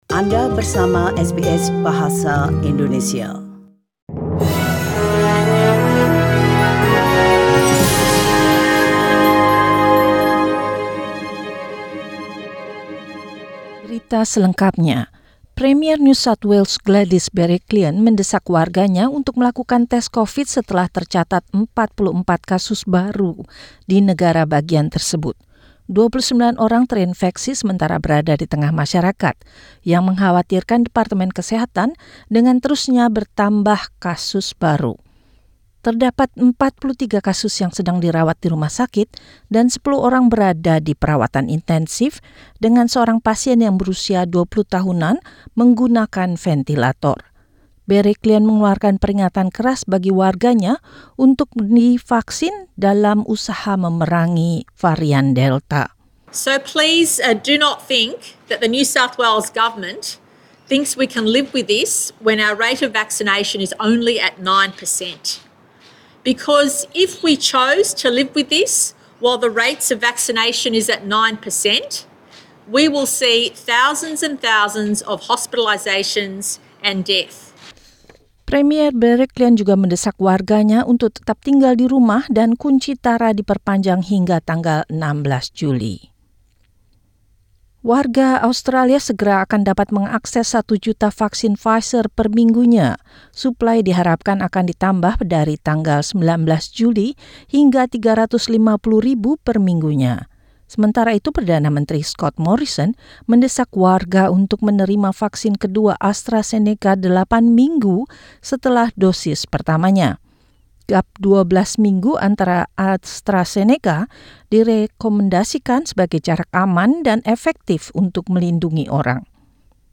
SBS Radio News Report delivered in Indonesian, Friday, 9 July 2021